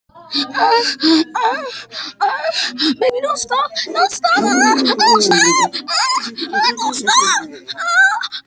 dog fight